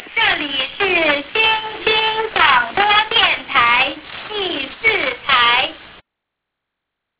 Each channel identifies itself at the beginning of the broadcasts as "the third" or "the fourth" program.